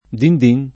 vai all'elenco alfabetico delle voci ingrandisci il carattere 100% rimpicciolisci il carattere stampa invia tramite posta elettronica codividi su Facebook dindin [ dind & n ] o din din [id.] escl. — voce imitativa del suono del campanello